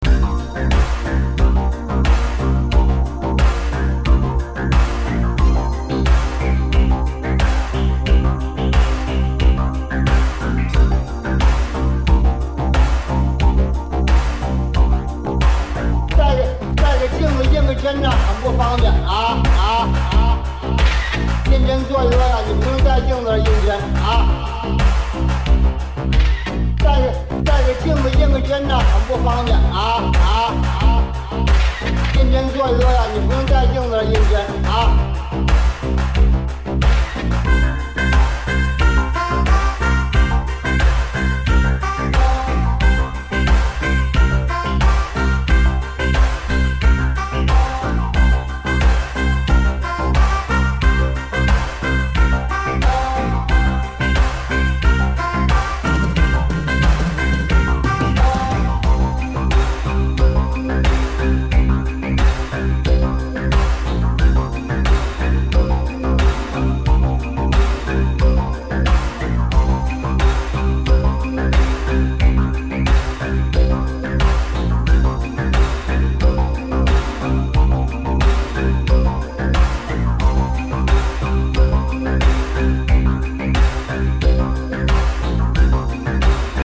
ディスコやブギー好きはもちろん、オブスキュア〜バレアリック、テクノ系のDJにもオススメです！